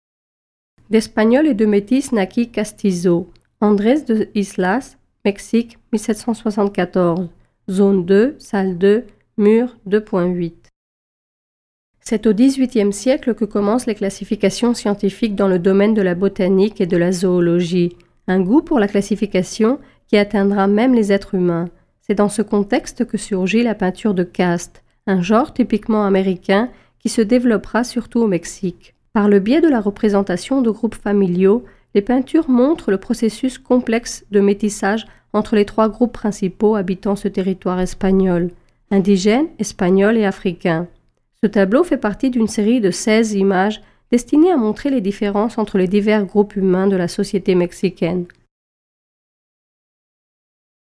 Audioguides par pièces